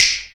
SD SNAP4.wav